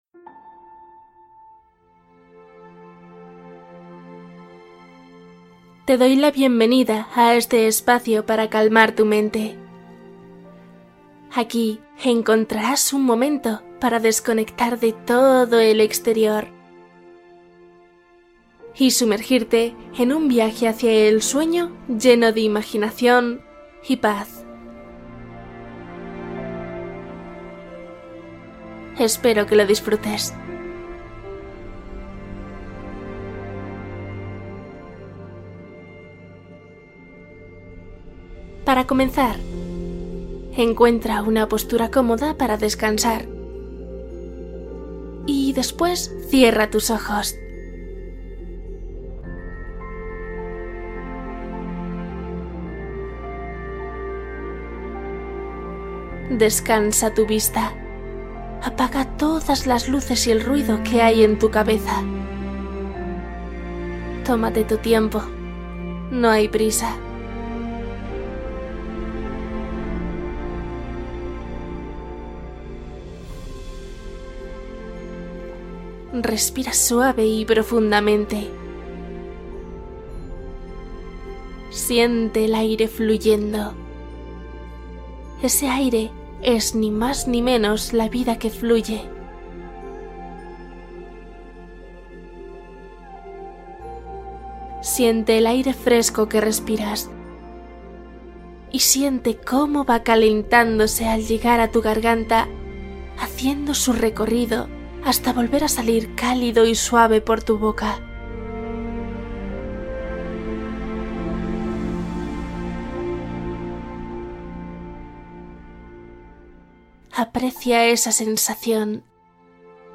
Cuento para dormir | Detén pensamientos y elimina preocupaciones